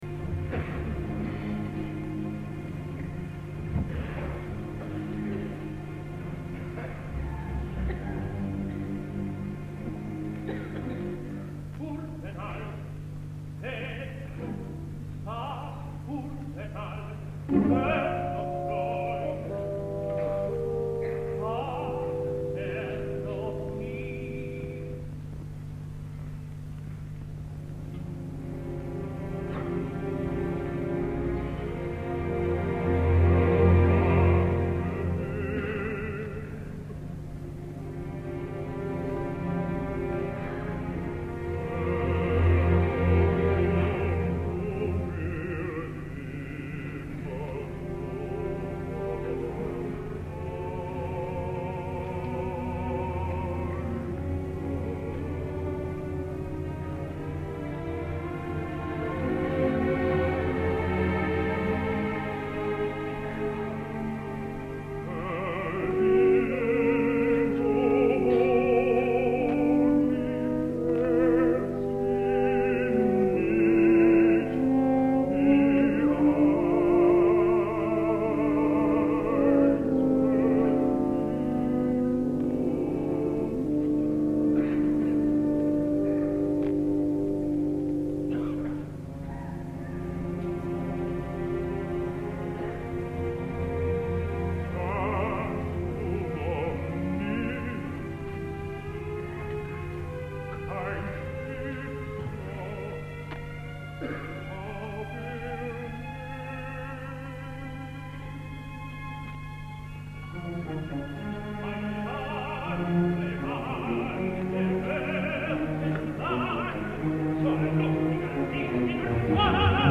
Kurwenal! He!, with Hans Hotter
Argentinean tenor.